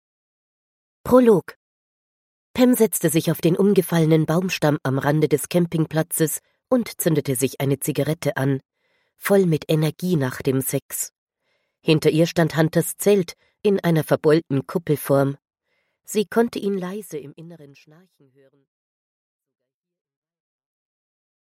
Аудиокнига Bevor Er Begehrt | Библиотека аудиокниг